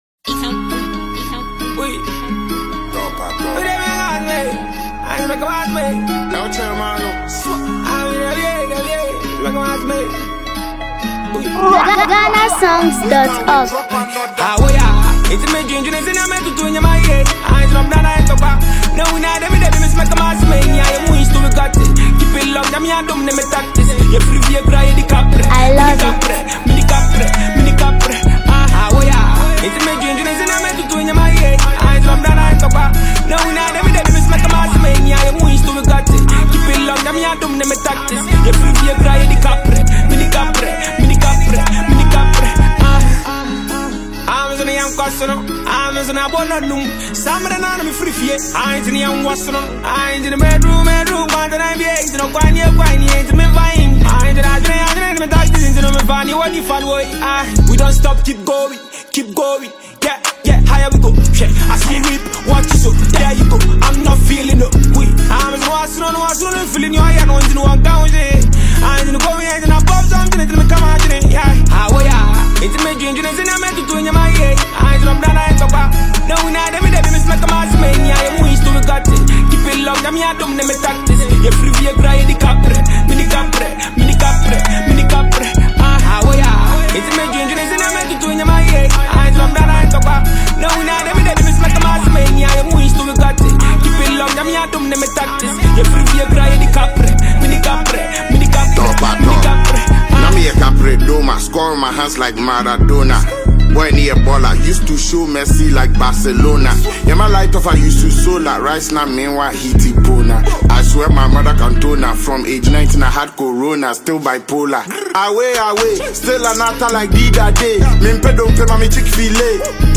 energetic and catchy Afrobeat songs